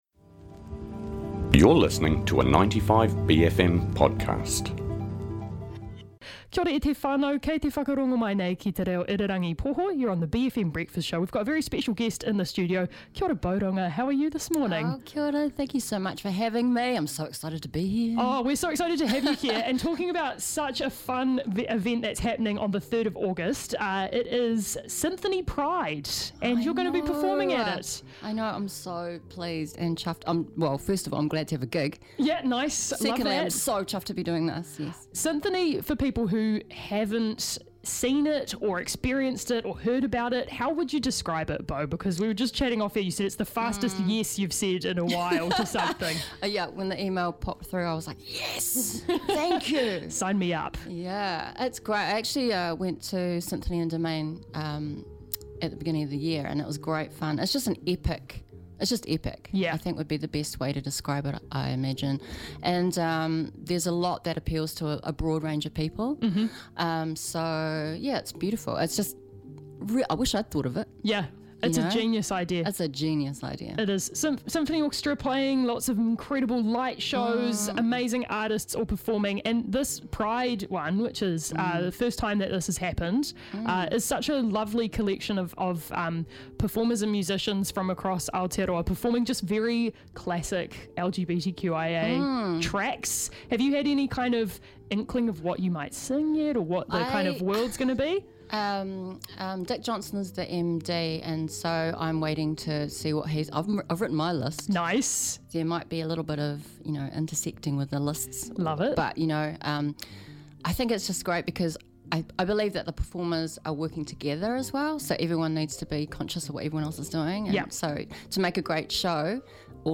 Boh Runga is in the studio for a conversation about Synthony Pride.